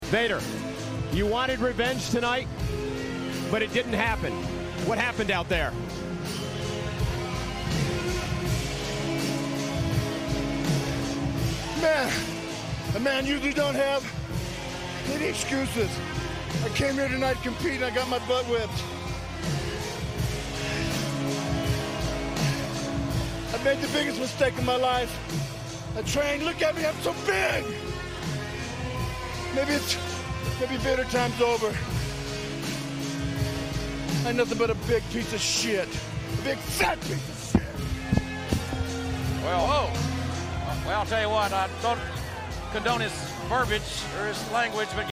In an incredible post-match promo,